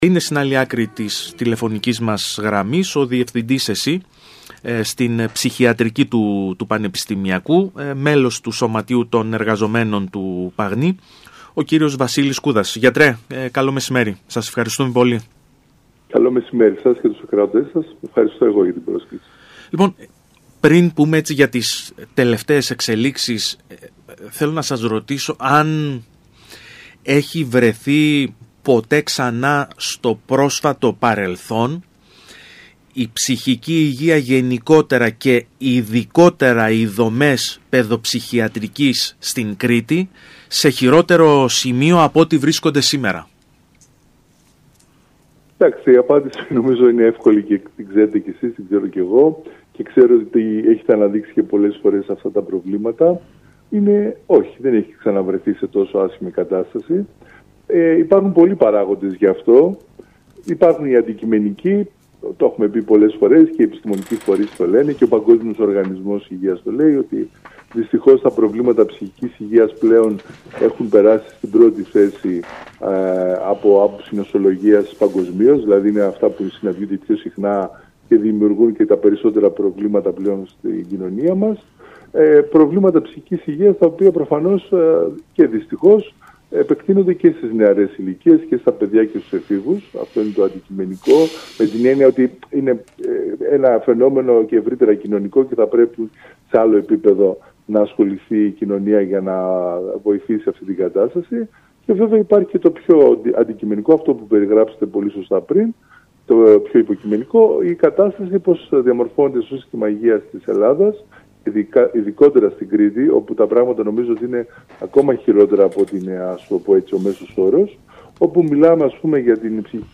δήλωσε στο ραδιόφωνο του ΣΚΑΪ Κρήτης